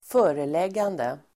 Uttal: [²f'ö:releg:ande]